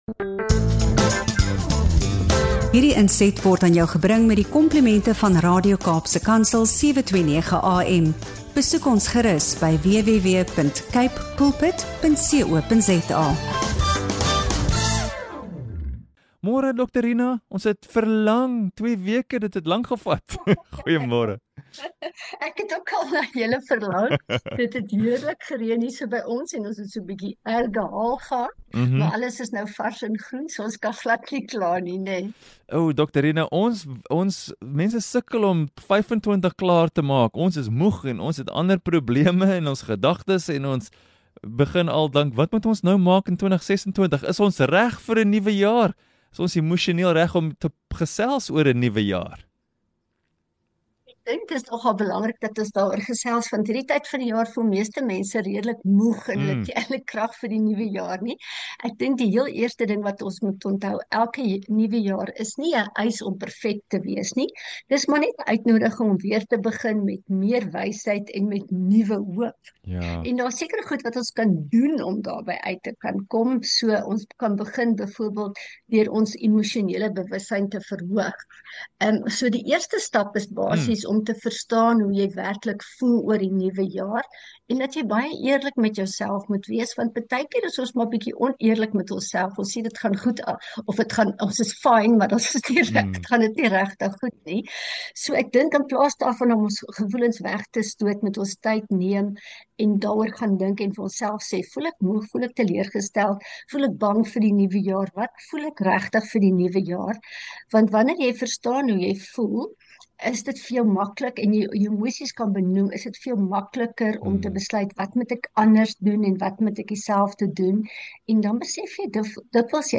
Hierdie gesprek fokus op hoe mense aan die einde van ’n uitputtende jaar weer hul emosionele energie, hoop en motivering kan herstel voordat hulle 2026 aanpak.